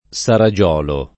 Saragiolo [ S ara J0 lo ] top. (Tosc.)